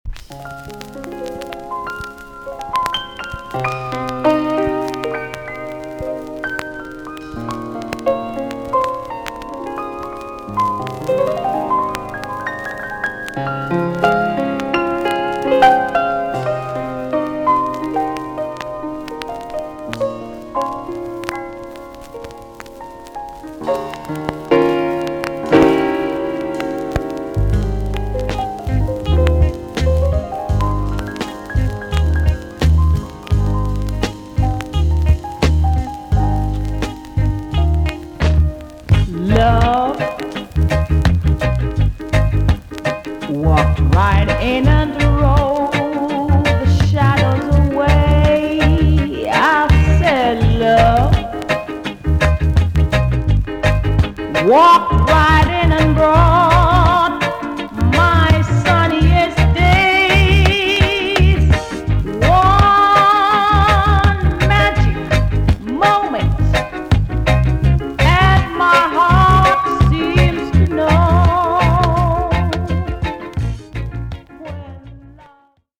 TOP >REGGAE & ROOTS
VG+~VG ok 軽いチリノイズが入ります。
RARE , NICE VOCAL TUNE!!